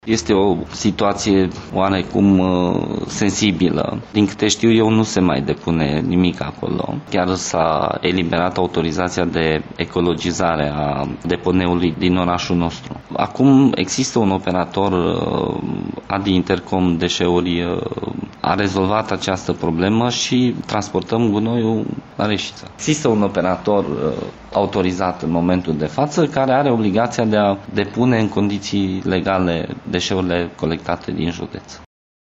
Primarul Eugen Cismăneanţu: